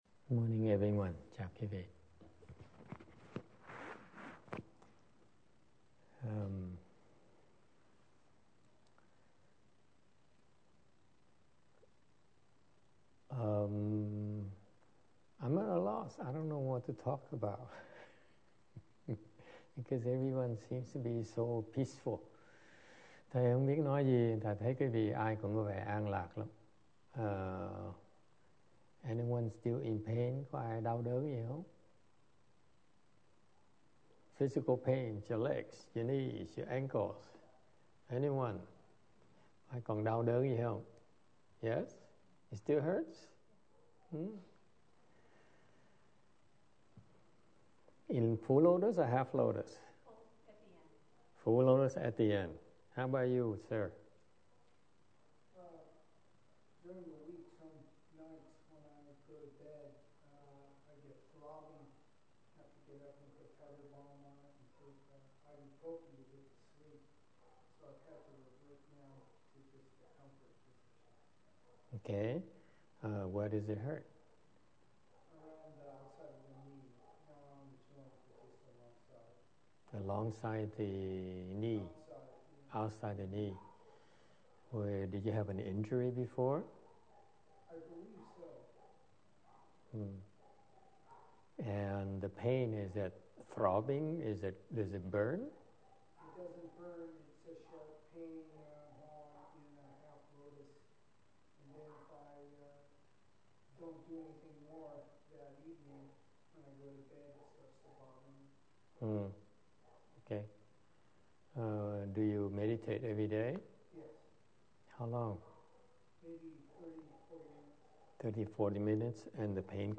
화두 법문法門 지침
ChanClass_160402_Hua_Tou_Dharma_Door_Instruction.mp3